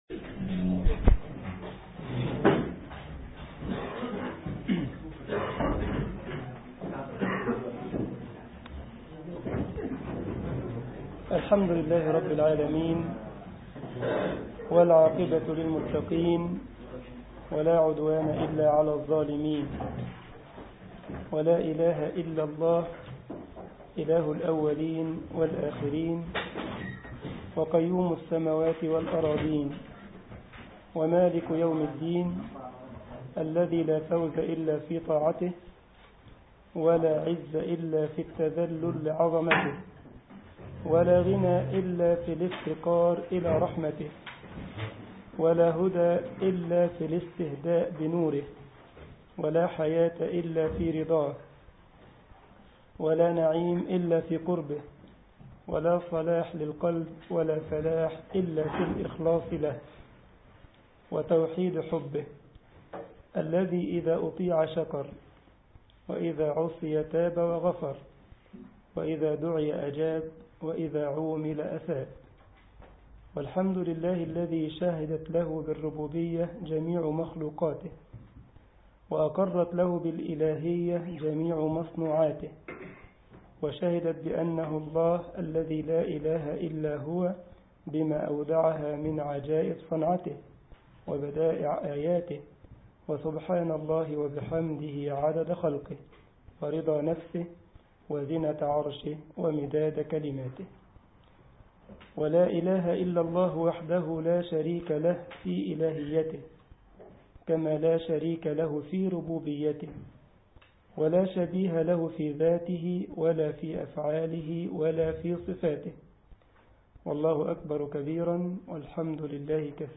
الجمعية الإسلامية بالسارلند ـ ألمانيا درس